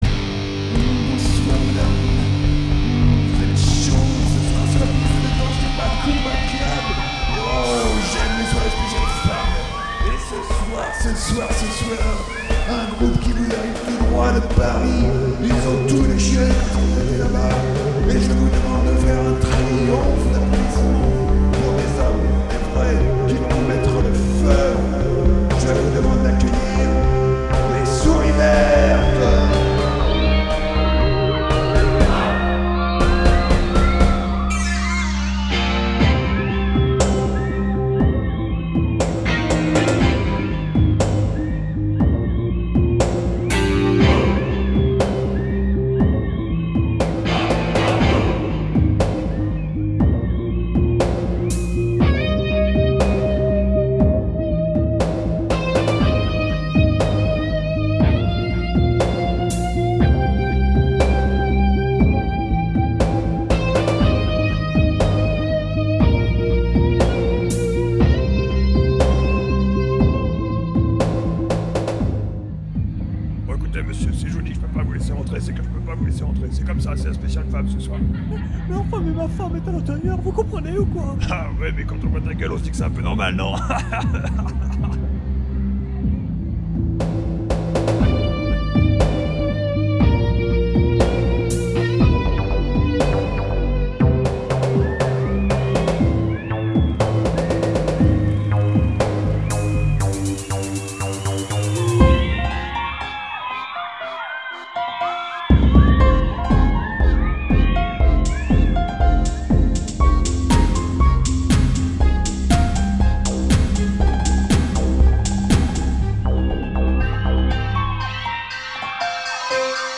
Je remarque qu'on est tous (enfin nous trois, enfin chez moi faut bien chercher) d'accords sur un rythme blues assez sympa, et tout le monde a rajouté des petits bruitages maison !!!
Les cuivres à 18" sont une idée brillante dans cette habillage technoïde.
Rendu sonore : 5/5. Samples parfaits, voix absolument démentielles, mixage au petit oignons, du travail de pro.
Le piano tache un peu je trouve, mais sinon c au poil !